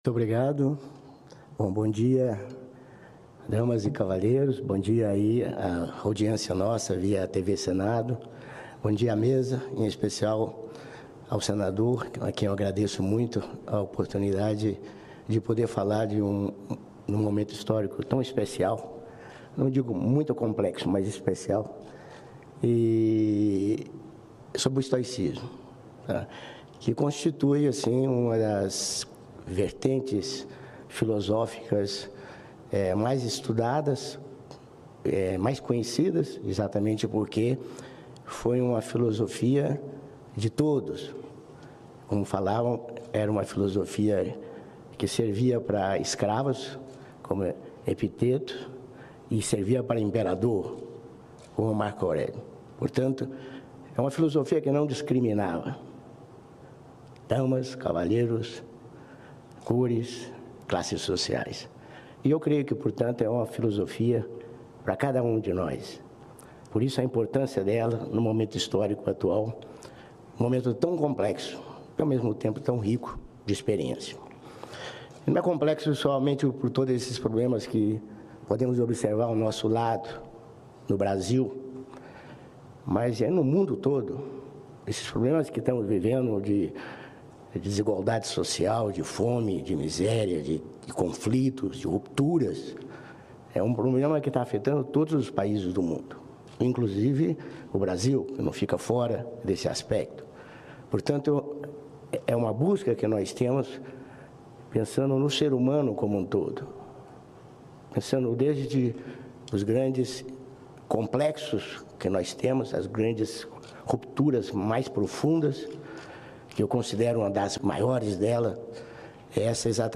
Na segunda-feira, dia 18 de setembro, a Organização Internacional Nova Acrópole participou da sessão especial em homenagem ao Estoicismo no plenário do Senado Federal em Brasília.